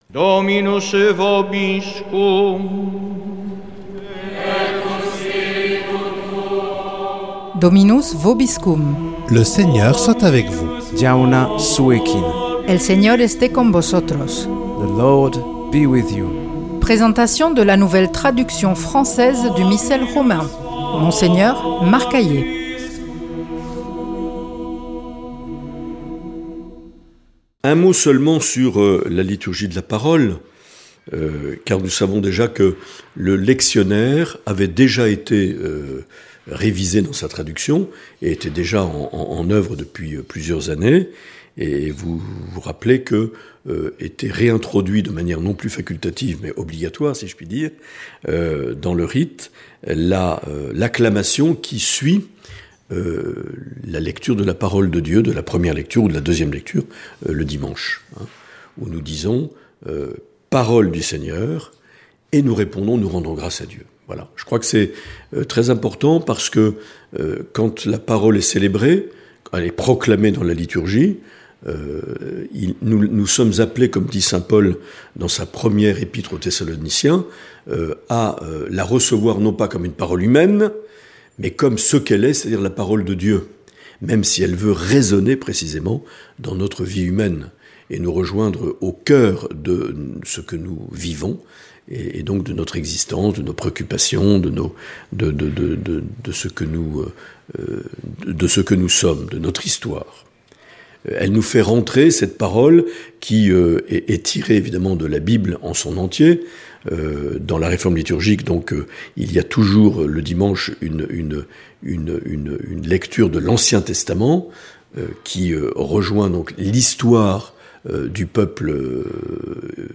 Présentation de la nouvelle traduction française du Missel Romain par Mgr Marc Aillet
Une émission présentée par